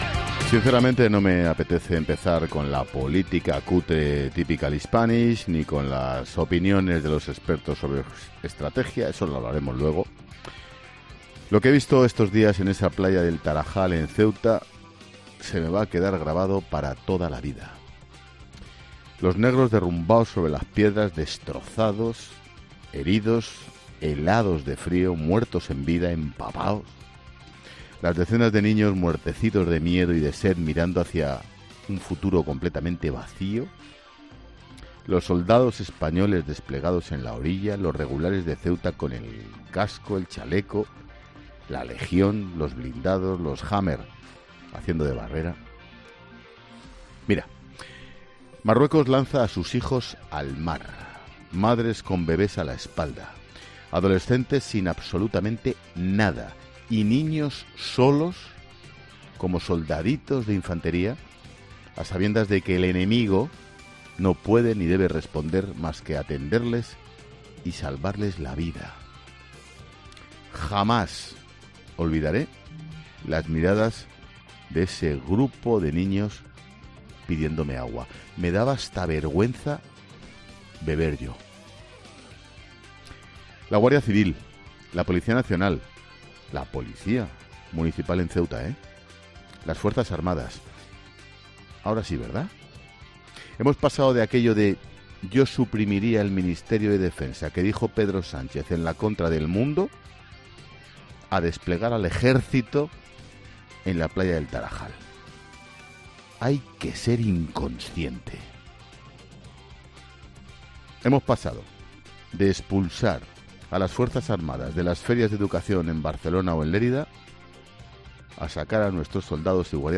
Monólogo de Expósito
El director de 'La Linterna', Ángel Expósito, ha recordado en su monólogo la situación crítica que vivió en primera persona en Ceuta